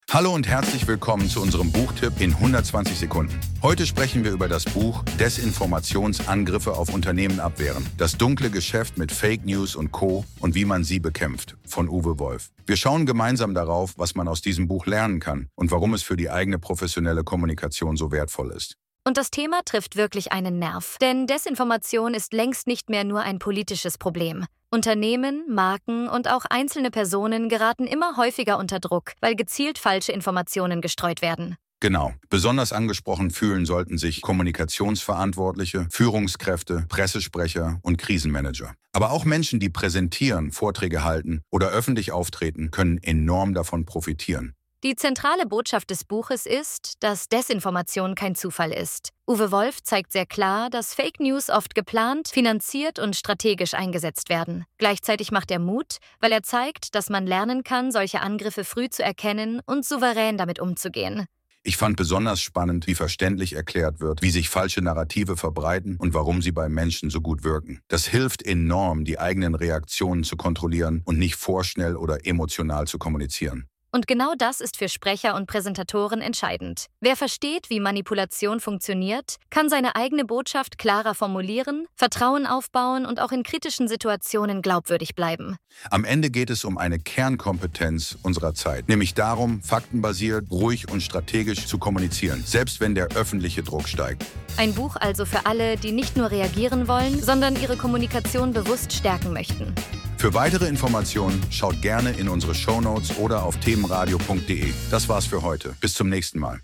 Unser Buchtipp in 120 Sekunden